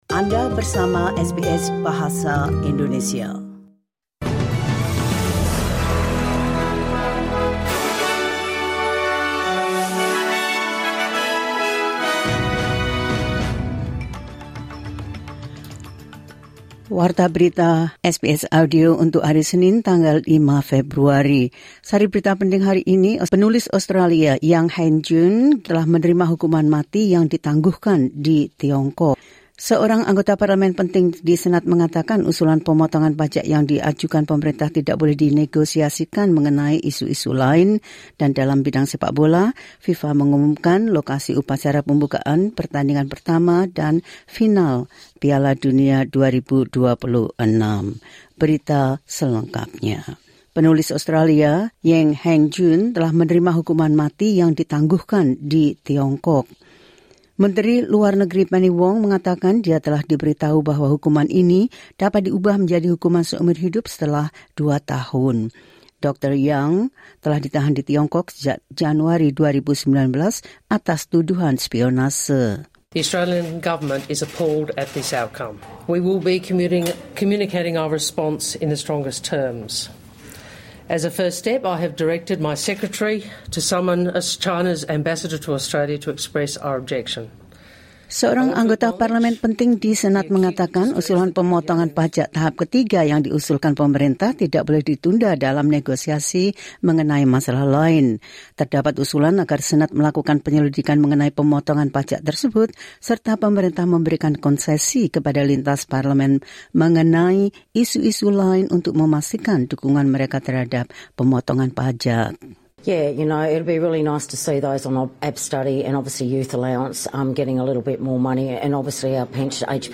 The latest news of SBS Audio Indonesian program – 05 Feb 2024